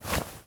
Player_Stand Up.wav